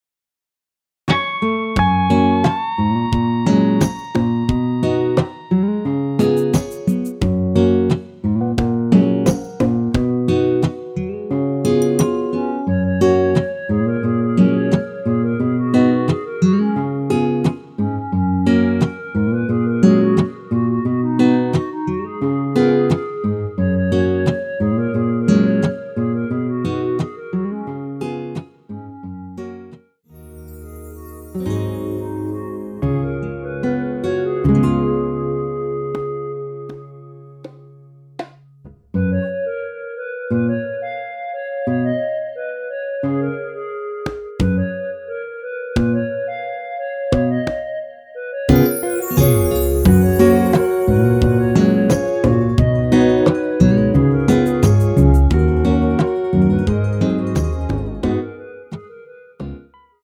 엔딩이 페이드 아웃이라 라이브 하시기 좋게 원곡의 3분11초에서 엔딩을 만들어 놓았습니다.
원키 멜로디 포함된 MR입니다.
F#
앞부분30초, 뒷부분30초씩 편집해서 올려 드리고 있습니다.